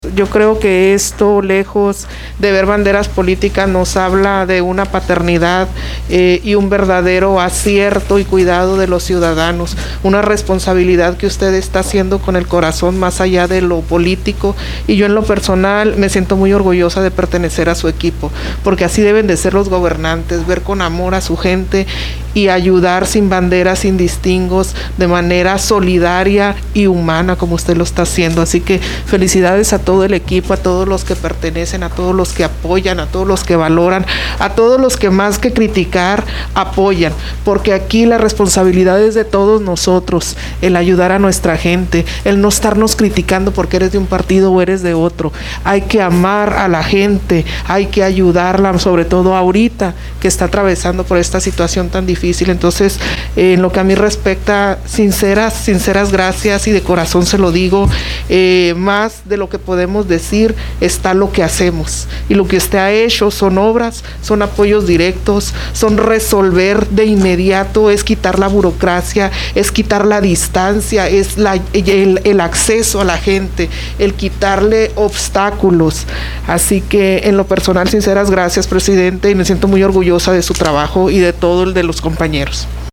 Durante la sesión de Cabildo, la regidora tomó la palabra para agradecer la postura social del gobierno local durante la contingencia actual provocada por el coronavirus.